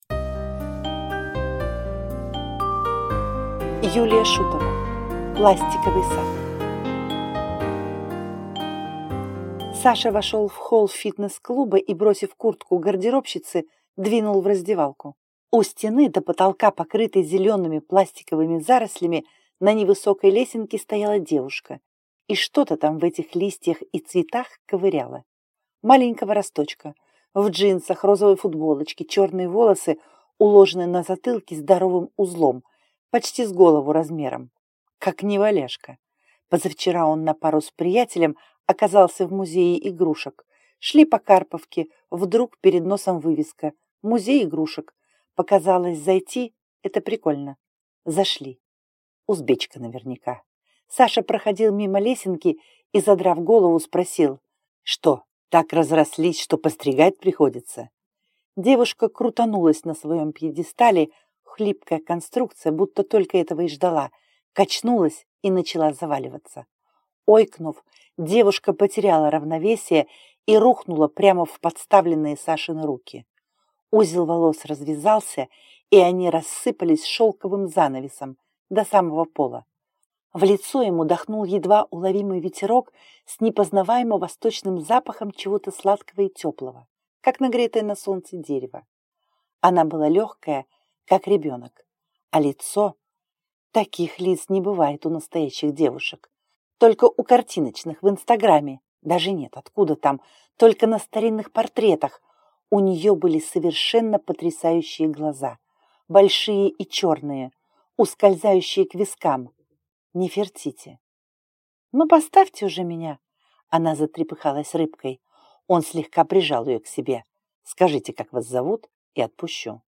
Аудиокнига Пластиковый сад | Библиотека аудиокниг